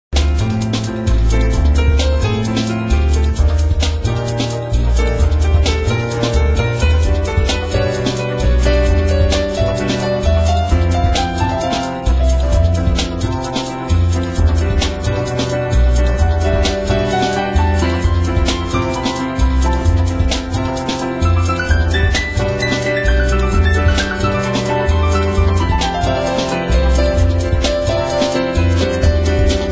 Piano
Bass
Drums
Vocals